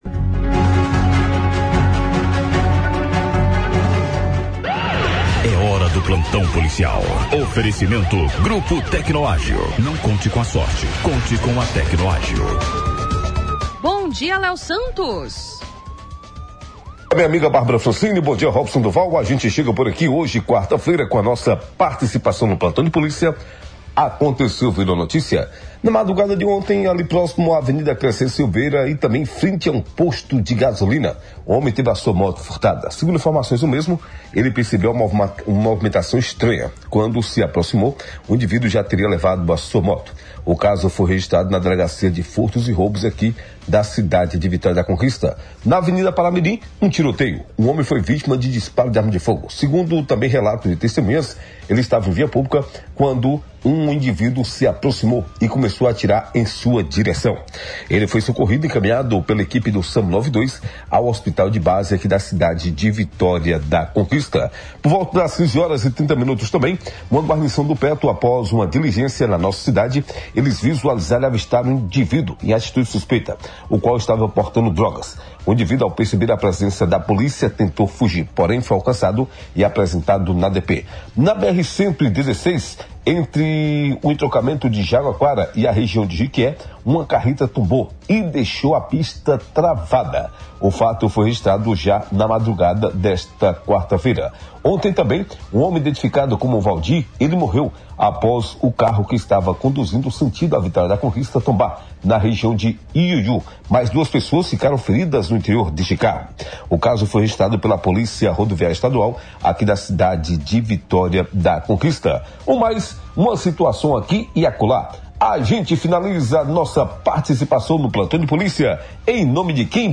transmitido ao vivo
agitava o recinto com sucessos do arrocha